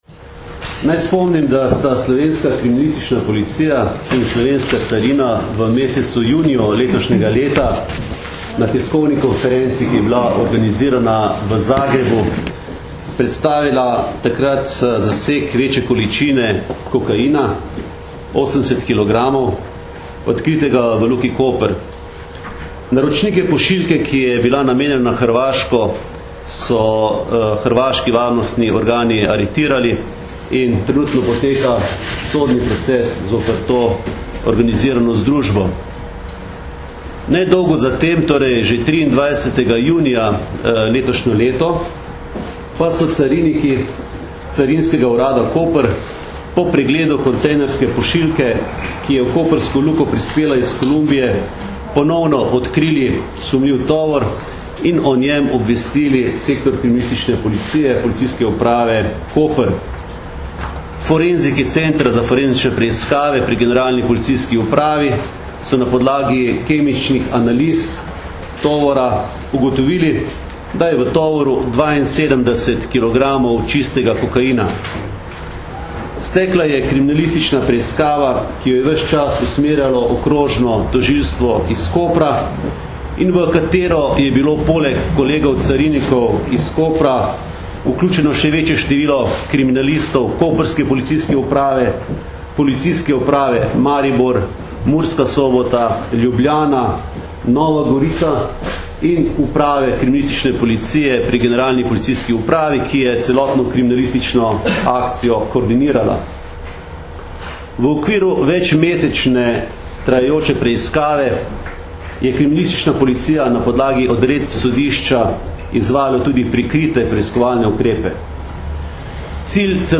Predstavniki Generalne policijske uprave in Carinske uprave RS so na današnji novinarski konferenci predstavili odkritje večje količine prepovedane droge in zaključke kriminalistične preiskave organizirane mednarodne kriminalne združbe, v kateri je bilo prijetih več osumljencev.
Zvočni posnetek izjave mag. Aleksandra Jevška (mp3)